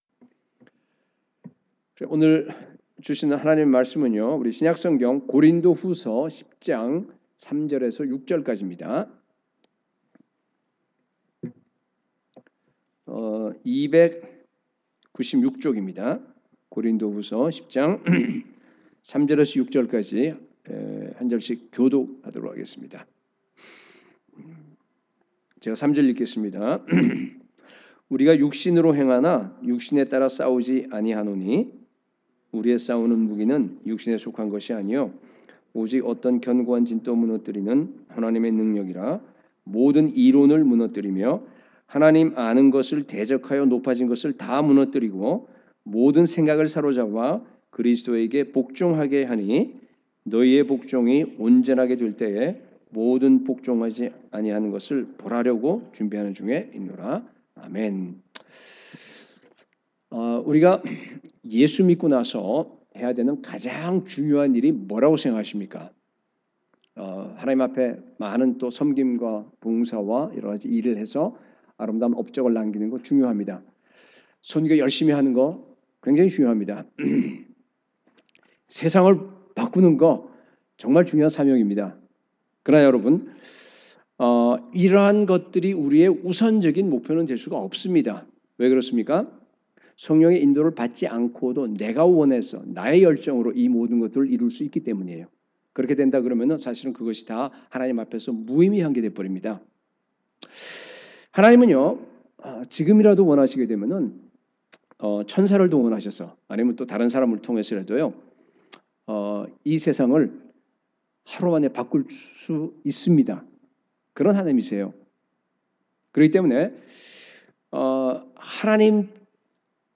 설교